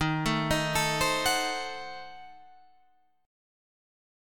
D#dim7 chord